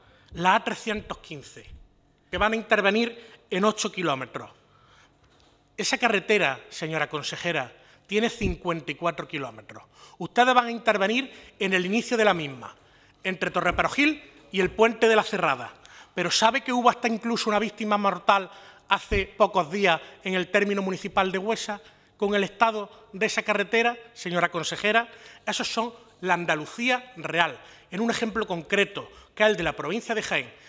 Comisión de Fomento
Cortes de sonido